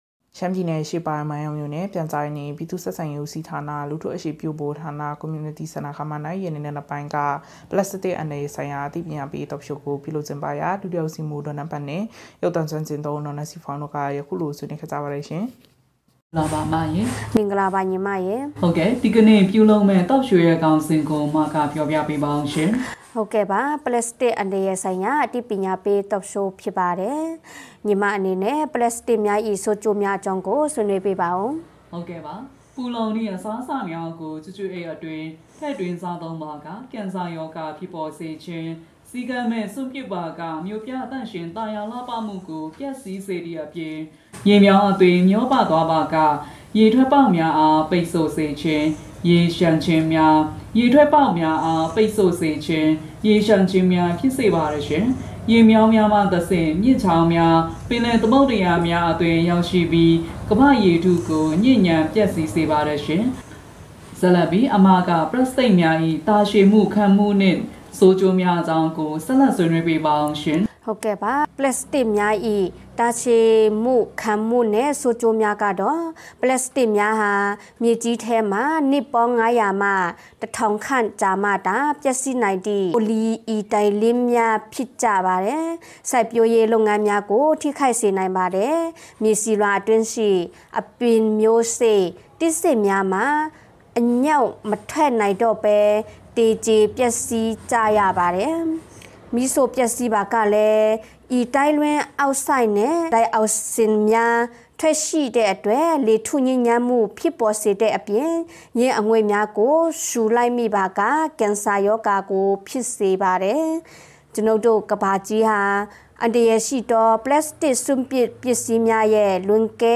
မိုင်းယောင်းမြို့၌ ပလတ်စတစ်အန္တရာယ်ဆိုင်ရာအသိပညာပေး Talk Show ပြုလုပ်